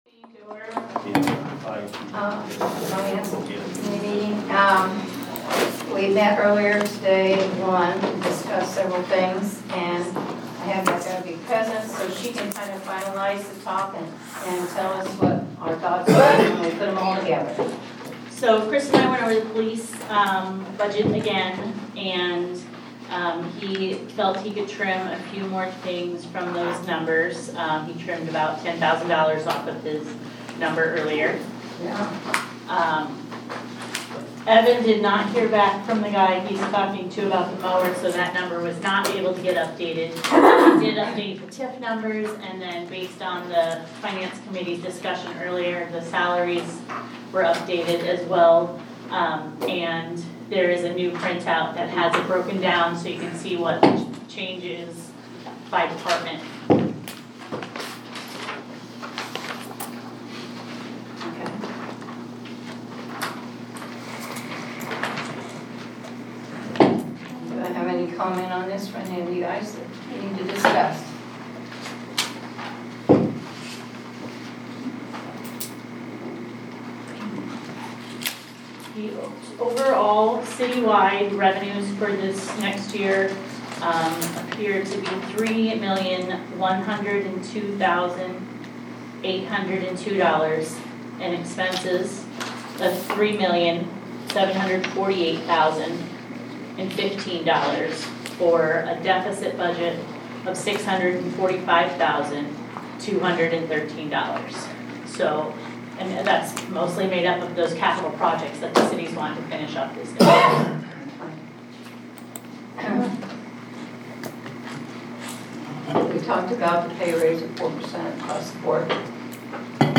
February 25th, 2025 City Council Meeting Audio